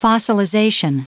Prononciation du mot fossilization en anglais (fichier audio)
Prononciation du mot : fossilization